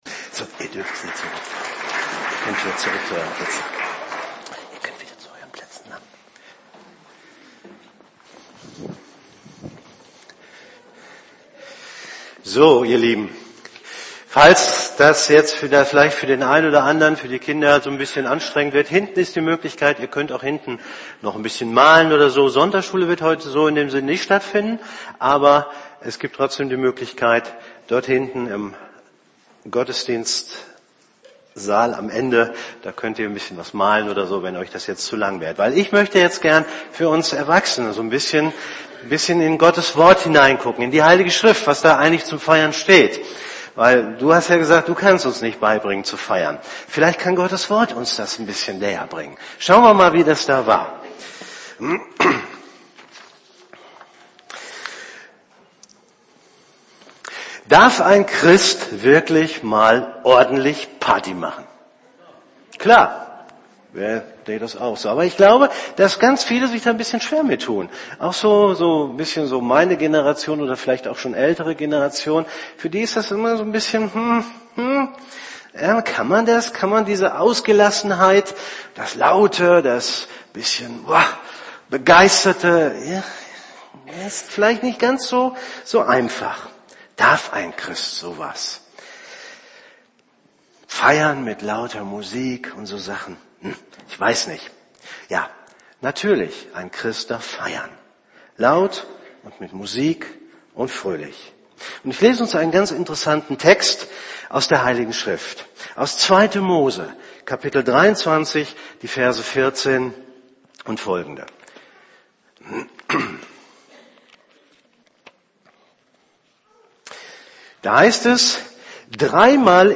predigt.mp3